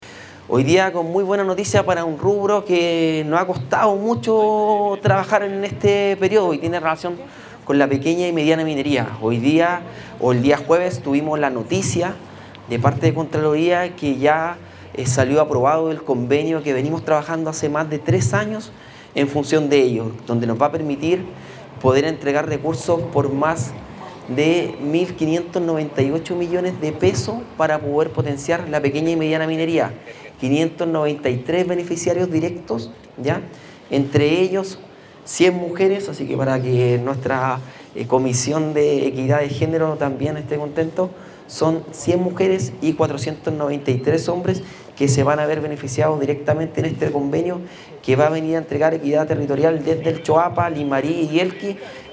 El Gobernador Regional, Darwin Ibacache, destacó el esfuerzo conjunto que permitió destrabar el proyecto.
GOBERNADOR-REGIONAL-DARWIN-IBACACHE-1.mp3